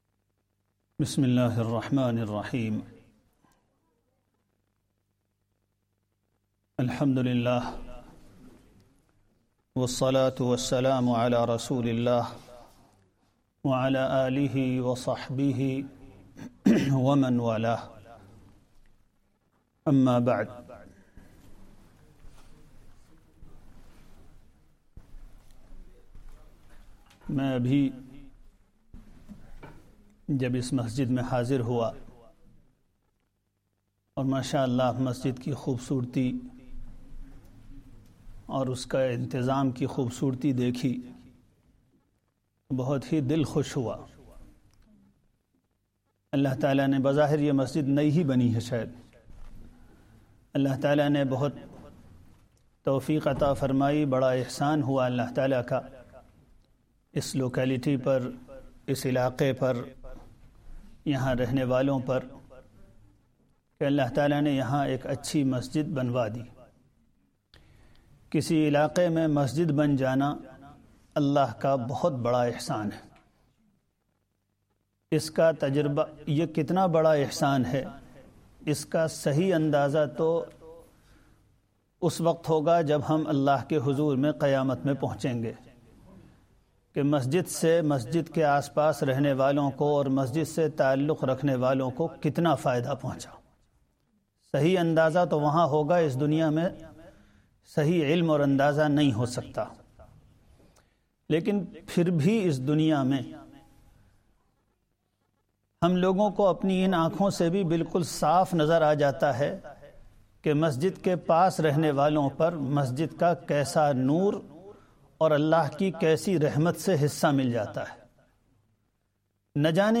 Ramadhan Day 11 Asr Talk